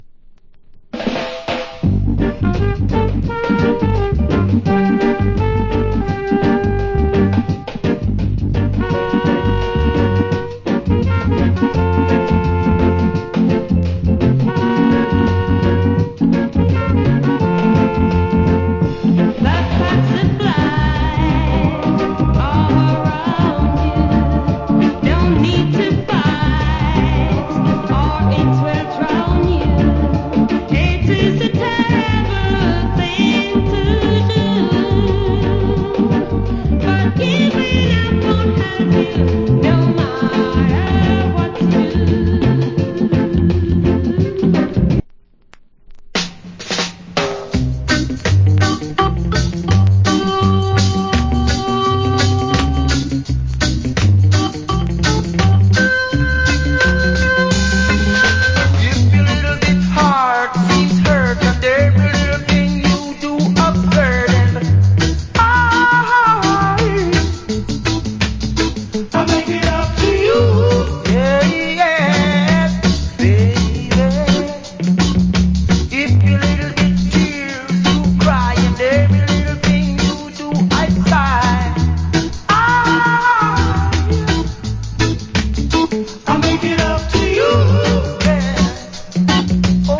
Wicked Early Reggae Vocal.